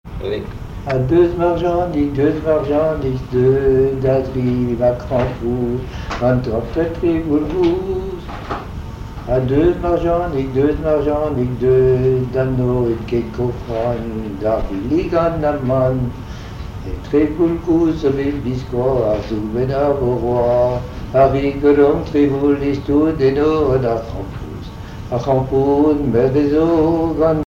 chant en breton
Pièce musicale inédite